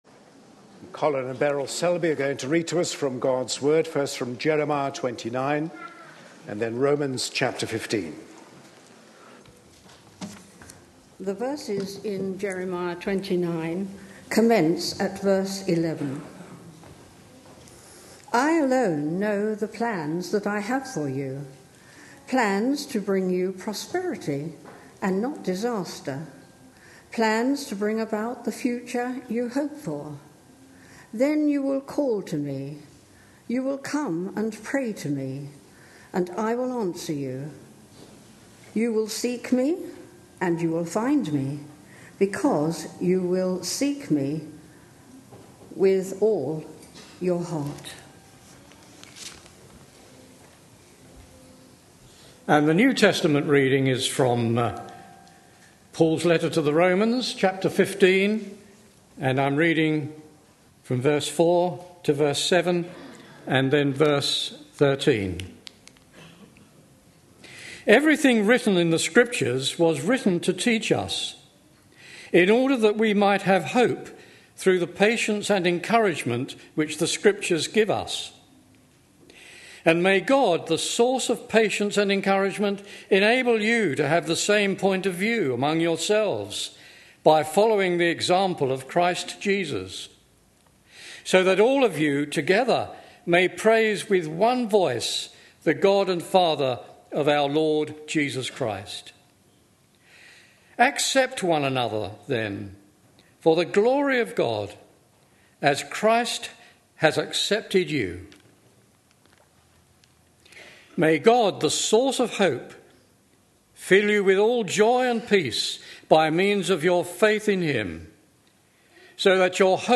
A sermon preached on 2nd March, 2014.